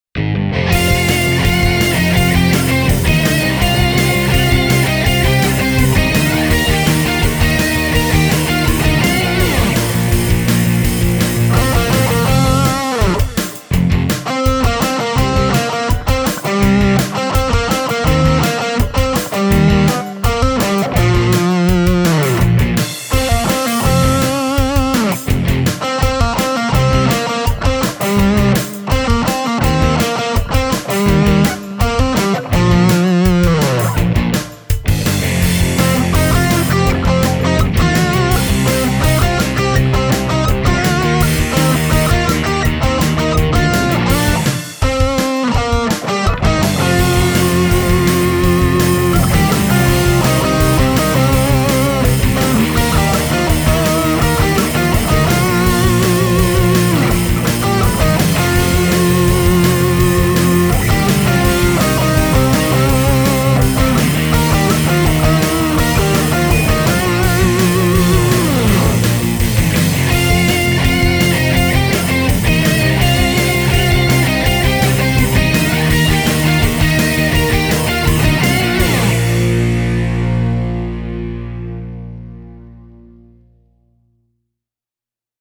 As a demo song I recorded short cover version